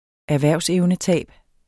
Udtale [ æɐ̯ˈvæɐ̯ˀwsεwnəˌtæˀb ]